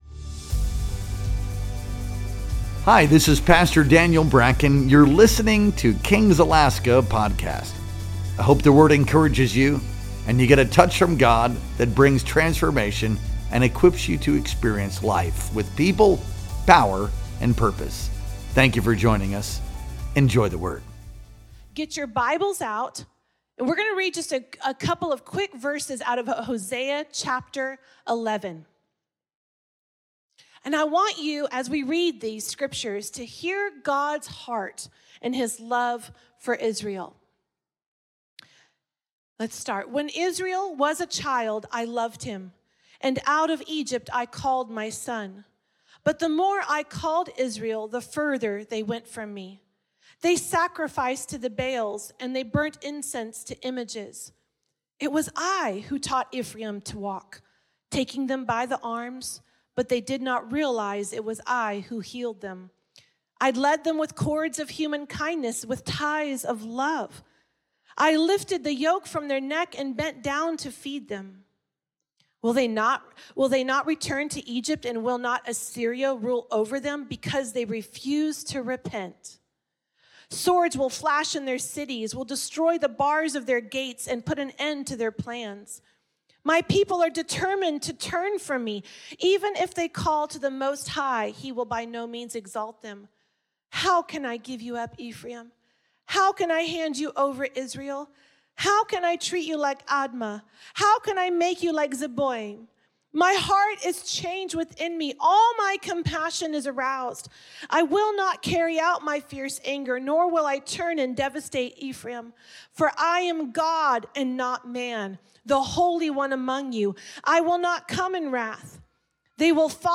Our Sunday Night Worship Experience streamed live on June 29th, 2025.